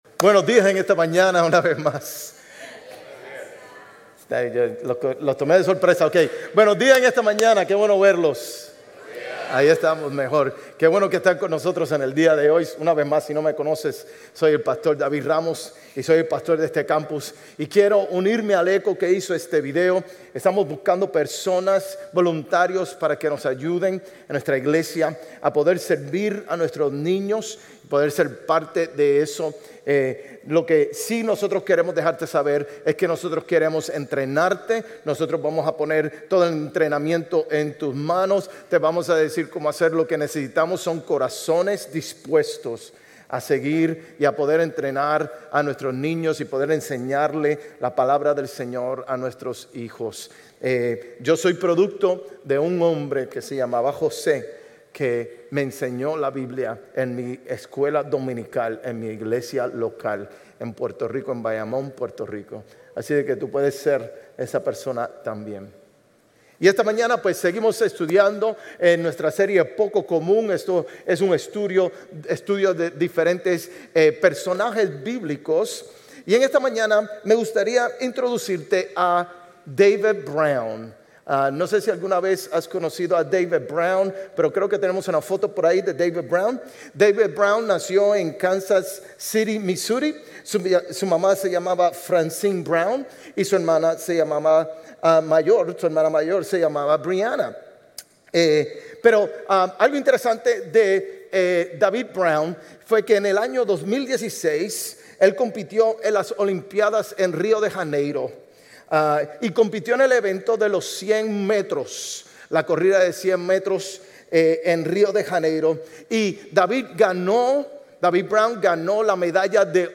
Sermones Grace Español 7_27 Grace Espanol campus Jul 28 2025 | 00:46:18 1x 00:00 / 00:46:18 Subscribe Share RSS Feed Share Link Embed